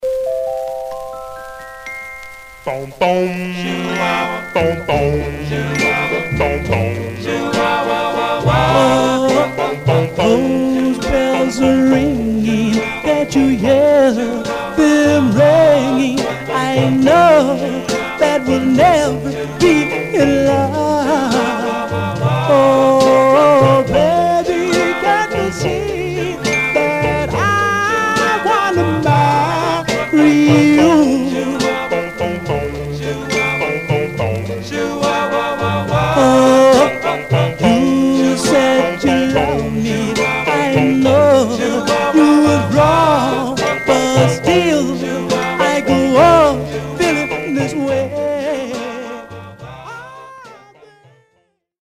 Surface noise/wear Stereo/mono Mono
Male Black Groups